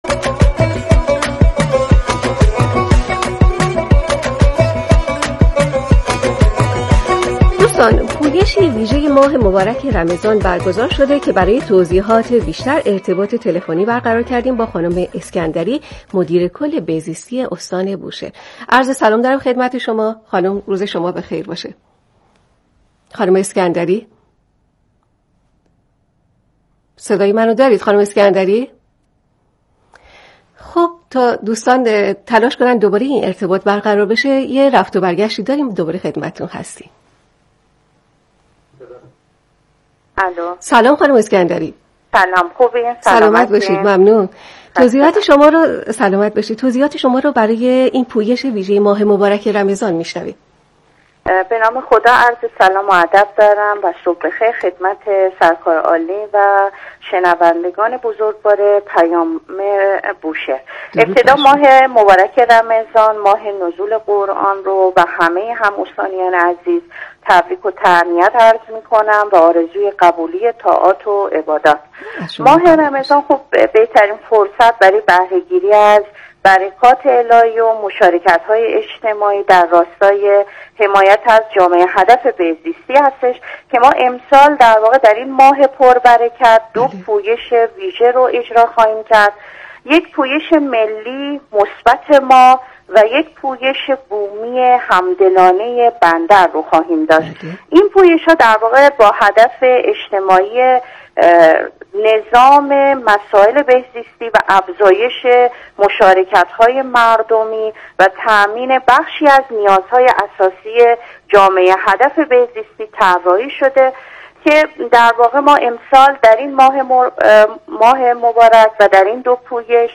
به گزارش روابط عمومی اداره کل بهزیستی استان بوشهر، مهرناز اسکندری، مدیرکل بهزیستی استان بوشهر، در گفت‌وگوی زنده تلفنی با برنامه رادیویی “پیام بوشهر” صدا و سیمای مرکز بوشهر ضمن تبریک ماه رمضان، از اجرای دو پویش ویژه با عنوان «پویش ملی مثبت ماه» و «پویش بومی همدلان بندر» در این ماه خبر داد.